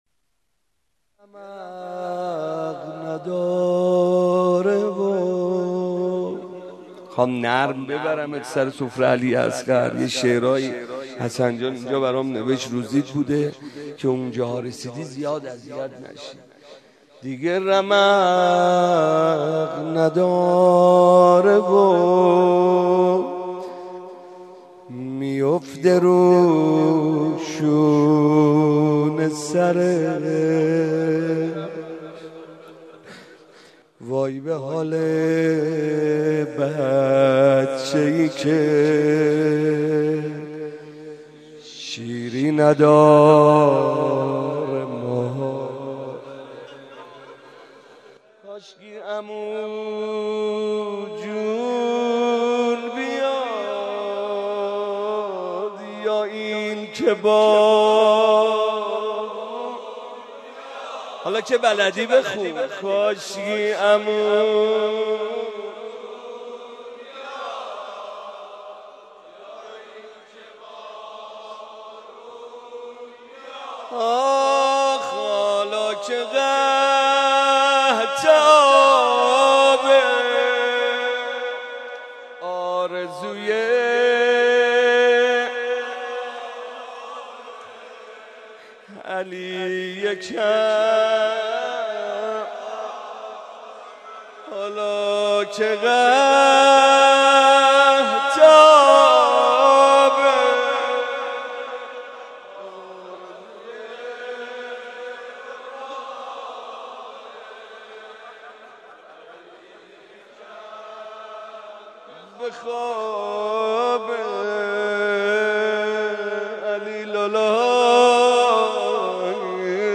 03.rozeh.mp3